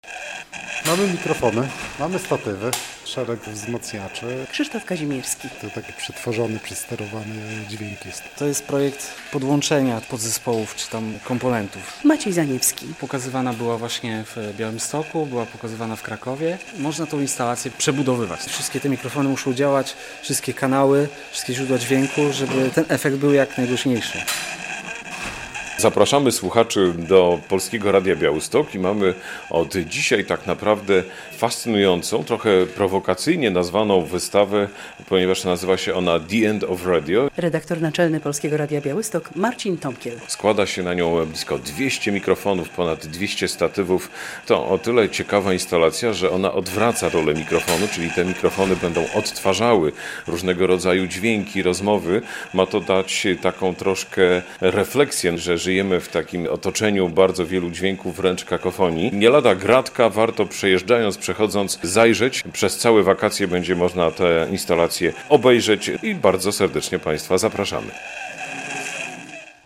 Galeria Arsenał w Polskim Radiu Białystok - relacja